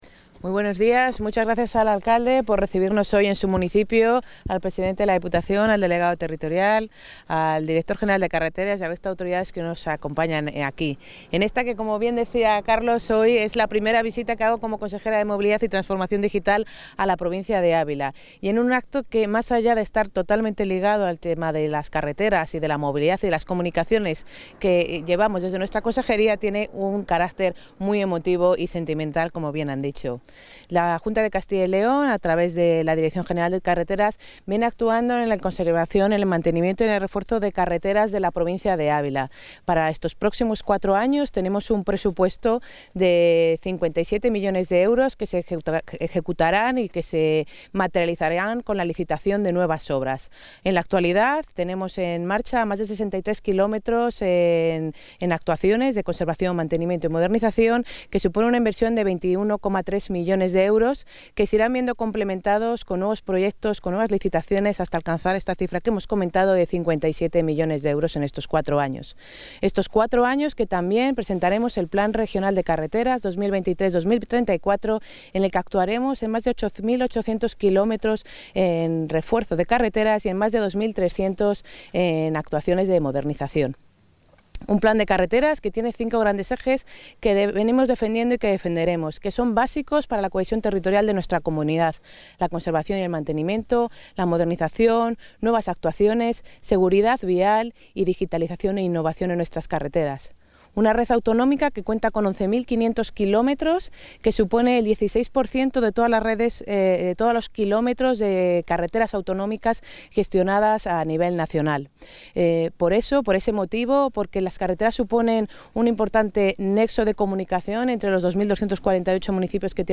Declaraciones de la consejera.